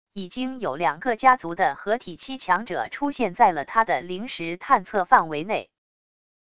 已经有两个家族的合体期强者出现在了他的灵识探测范围内wav音频生成系统WAV Audio Player